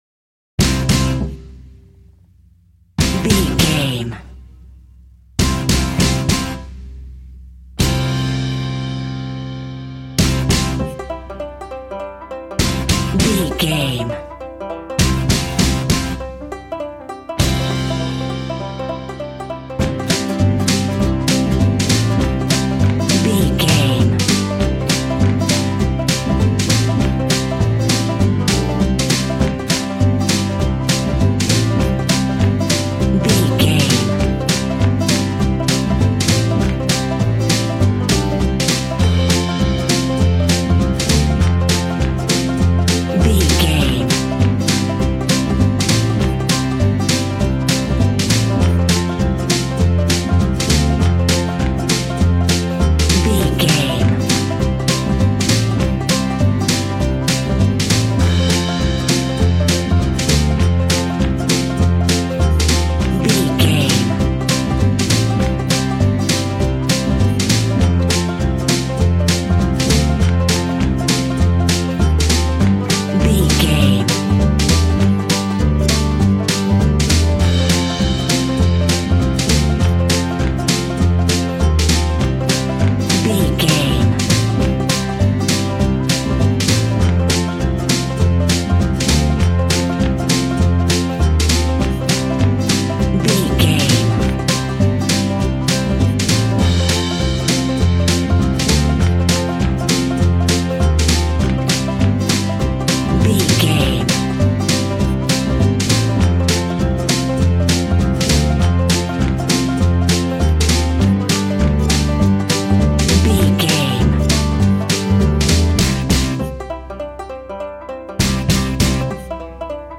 Ionian/Major
Fast
bouncy
positive
double bass
drums
acoustic guitar